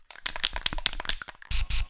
spray.ogg